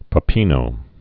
(pə-pēnō)